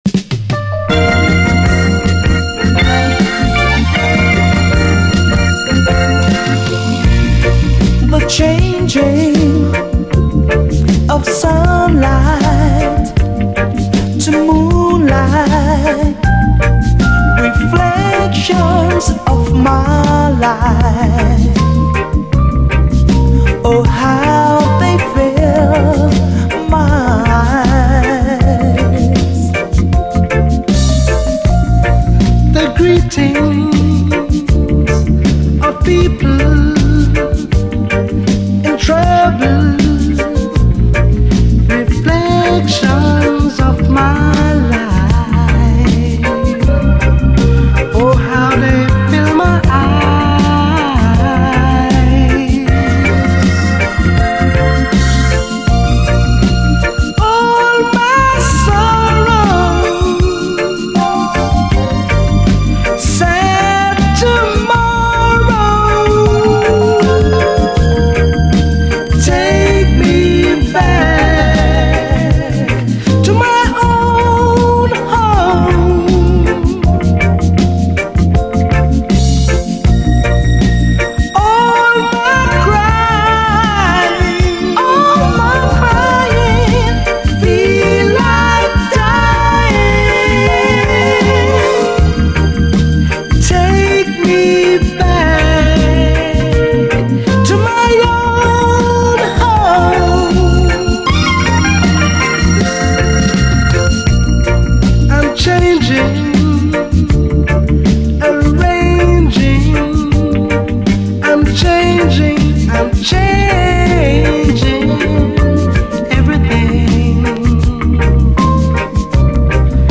REGGAE, 7INCH